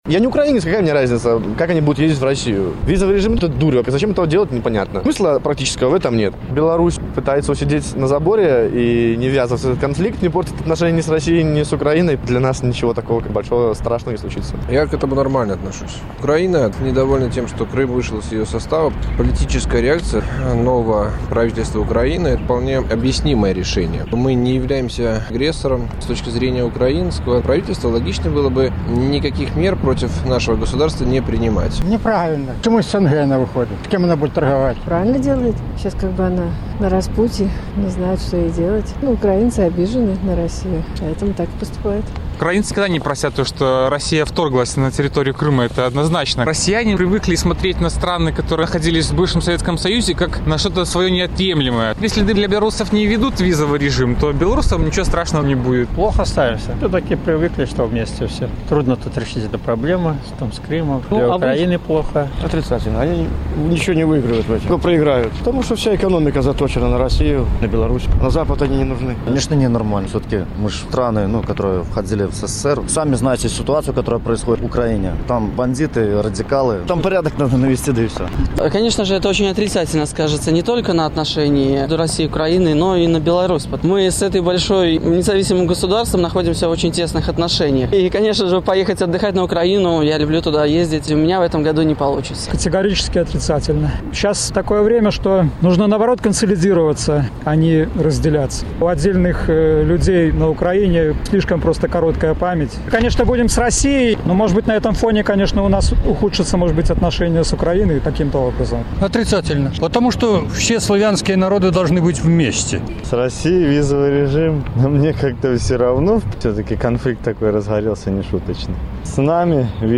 Адказвалі жыхары Магілёва.